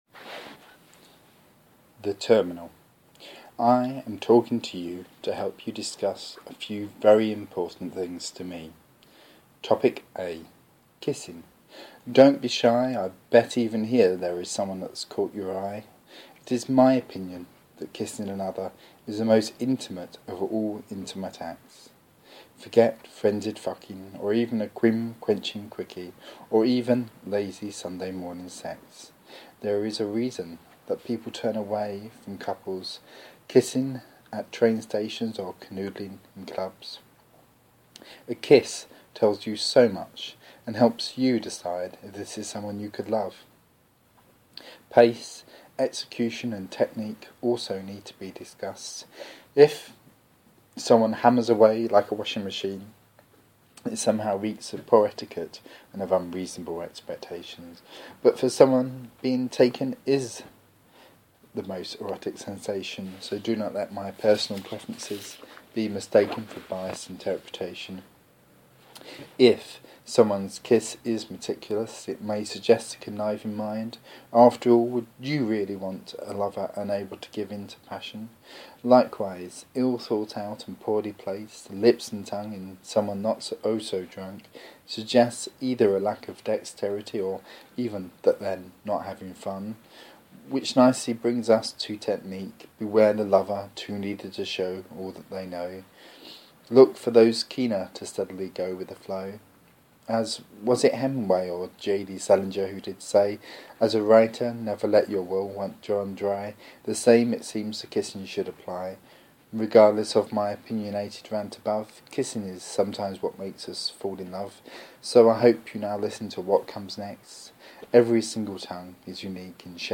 A long ranty poem